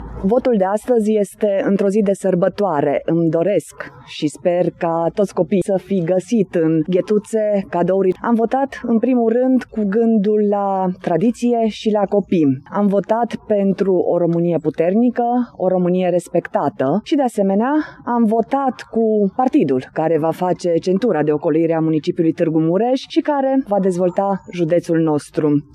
Mara Togănel și-a exprimat opțiunea electorală la Secția de Votare nr. 30 din Tg. Mureș: